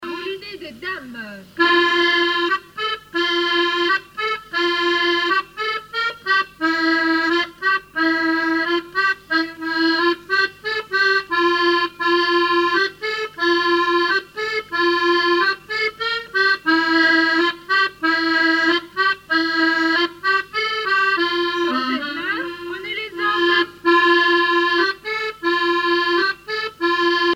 danse : quadrille : moulinet
Musique du quadrille local
Pièce musicale inédite